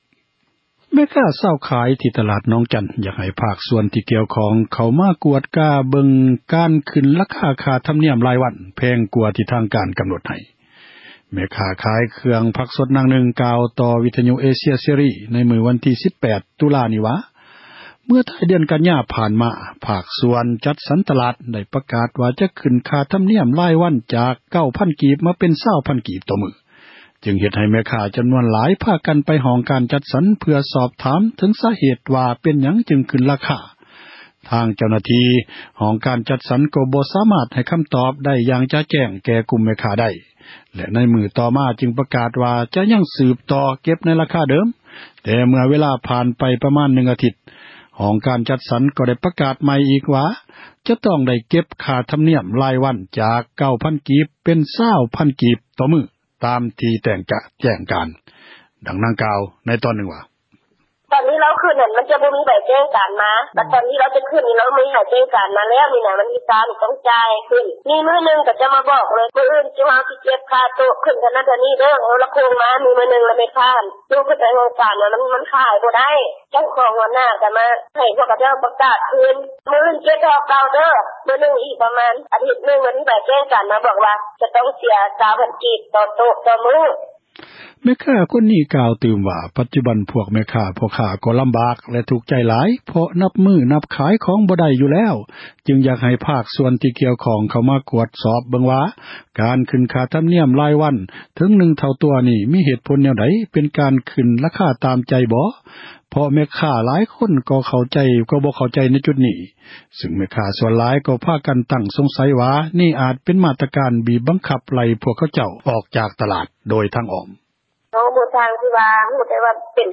ແມ່ຄ້າຂາຍຜັກນາງນຶ່ງ ກ່າວຕໍ່ຜູ້ສື່ຂ່າວ ເອເຊັຽເສຣີ ໃນວັນທີ 18 ຕຸລາ ນີ້ວ່າ ເມື່ອທ້າຍເດືອນ ກັນຍາ ຜ່ານມາ ພາກສ່ວນຈັດສັນ ຕະຫຼາດ ໄດ້ປະກາດ ວ່າຈະຂຶ້ນ ຄ່າທຳນຽມ ຣາຍວັນ ຈາກ 9 ພັນກີບ ມາເປັນ 20 ພັນກີບ ຕໍ່ມື້, ຈຶ່ງເຮັດໃຫ້ ແມ່ຄ້າ ກວ່າຮ້ອຍຄົນ ພາກັນໄປ ຫ້ອງການຈັດສັນ ເພື່ອຖາມເຖິງ ສາເຫຕ ຂອງການ ຂຶ້ນຣາຄາ, ແຕ່ທາງ ເຈົ້າໜ້າທີ່ ຈັດສັນ ກໍ່ບໍ່ສາມາດ ໃຫ້ຄຳຕອບ ທີ່ຈະແຈ້ງ ແກ່ກຸ່ມ ແມ່ຄ້າໄດ້ ແລະ ໃນມື້ຕໍ່ມາ ຈຶ່ງ ປະກາດວ່າ ຈະຍັງ ສືບຕໍ່ ເກັບ ໃນຣາຄາເດີມ.